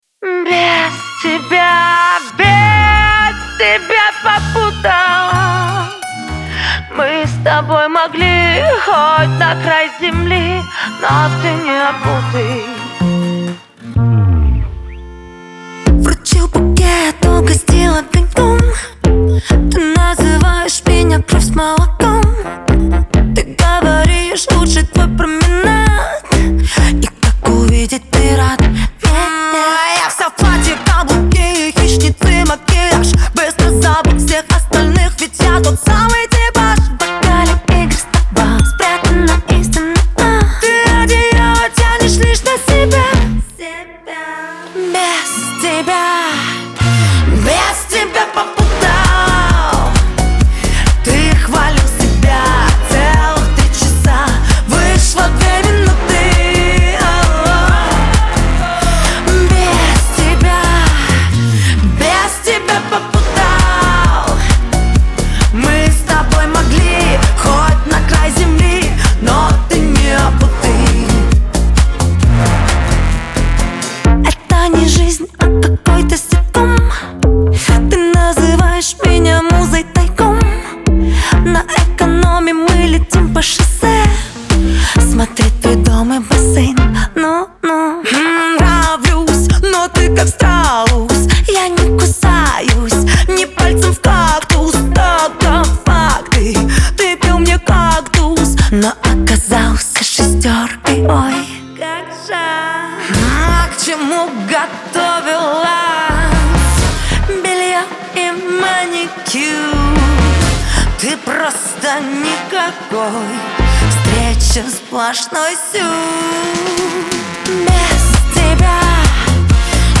Веселая музыка , Лирика